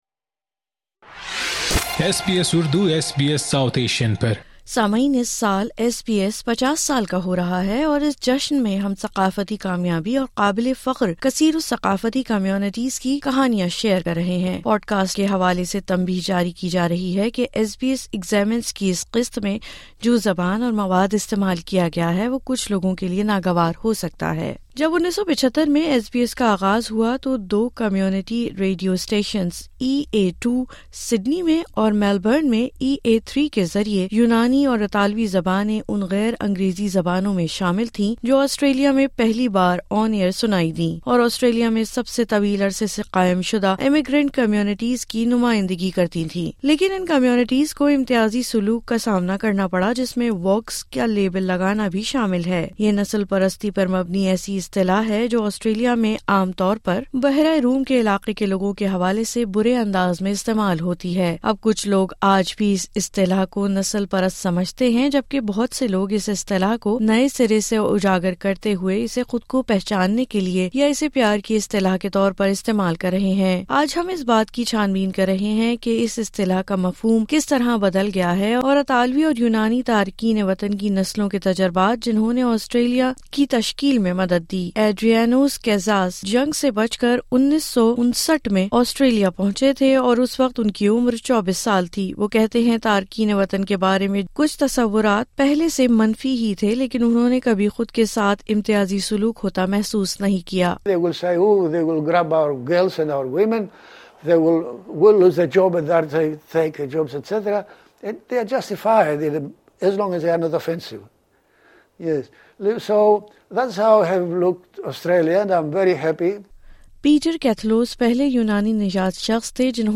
These Greek and Italian migrants share their experiences of Australia and their place within it.